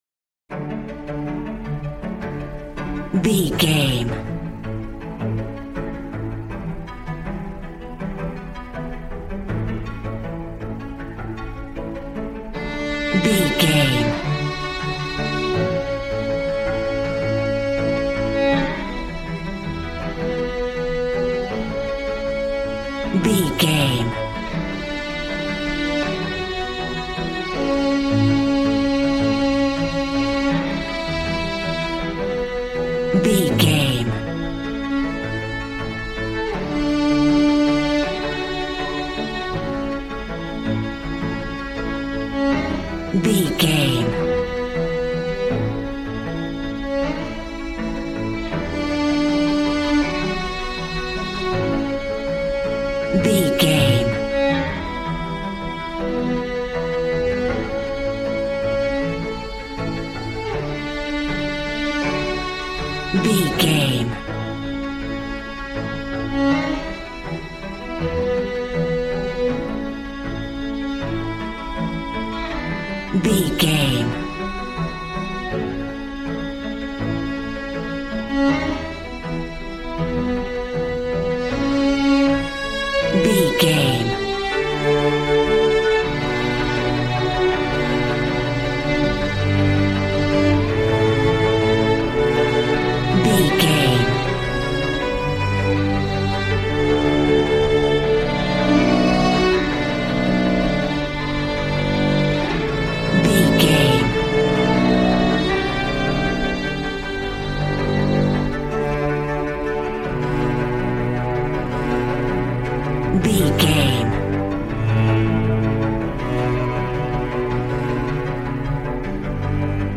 Ionian/Major
cello
violin
brass